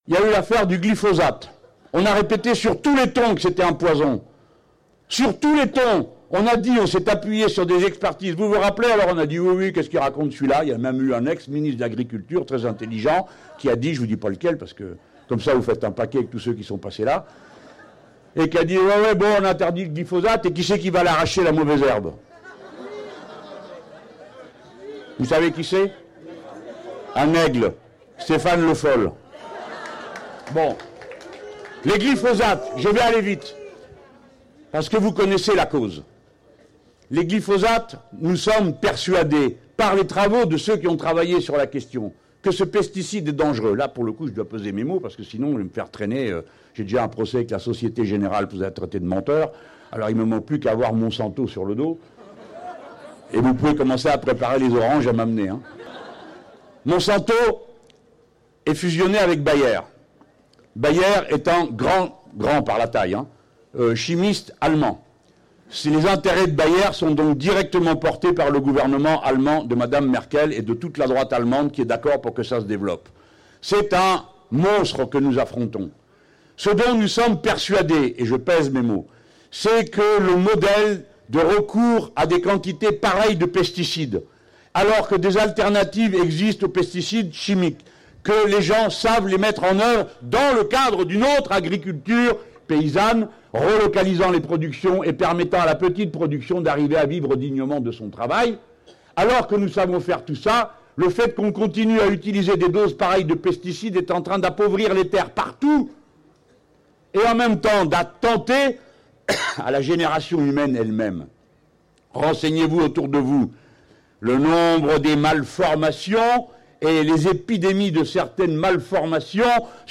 Nous allons inciter les Français à lui mettre une raclée démocratique», lance Jean-Luc Mélenchon devant quelque 2 000 personnes réunies à Marseille dans le cadre de l’université d’été de la France Insoumise.